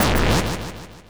snd_jump_ch1.wav